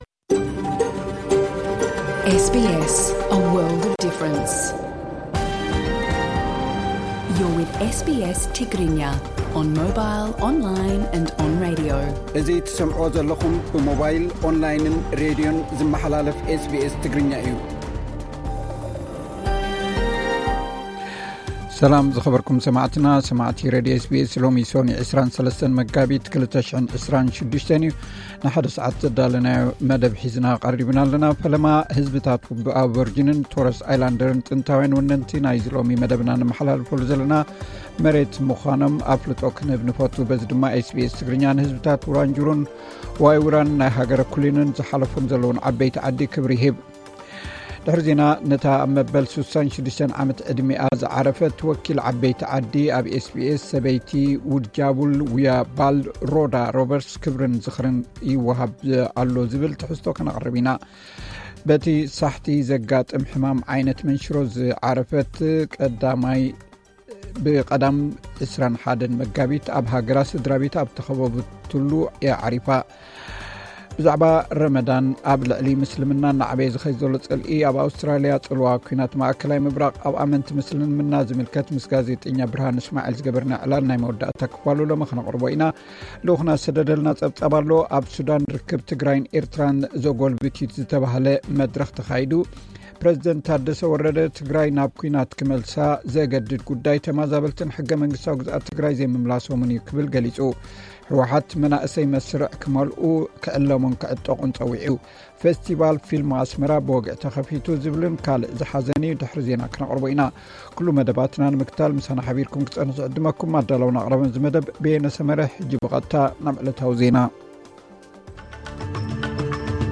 ልኡኽና ዝሰደደልና ጸብጻብ፡ ኣብ ሱዳን ርክብ ትግራይን ኤርትራን ዘጎልብት ዩ ዝተብሃለ መድረኽ ተኻይዱ ።